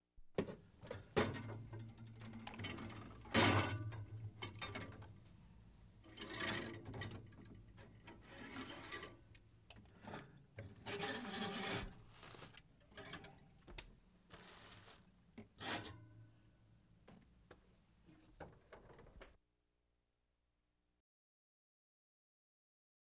Refrigerator Move Objects On Shelf Sound Effect
Download a high-quality refrigerator move objects on shelf sound effect.
refrigerator-move-objects-on-shelf.wav